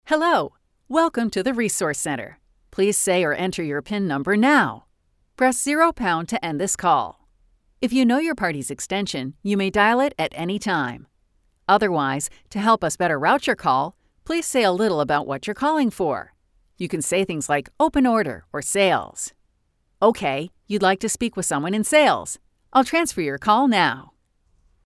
Voicemail